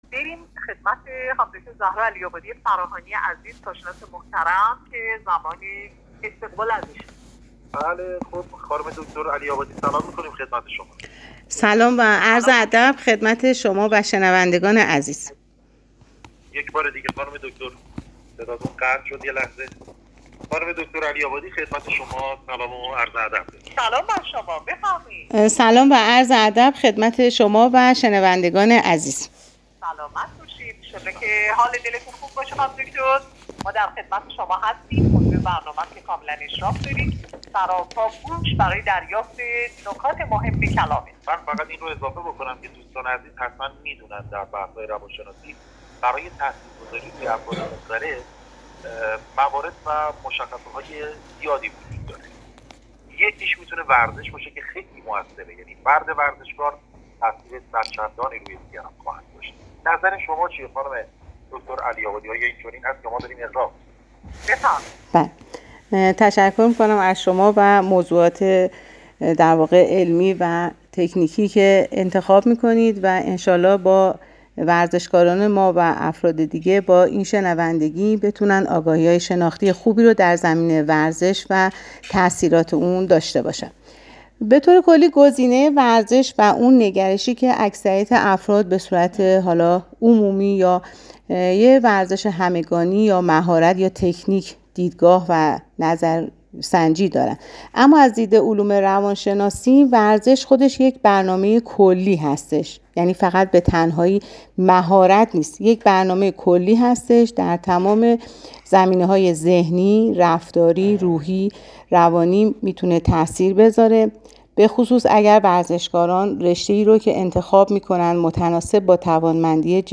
/گفت و گوی رادیویی/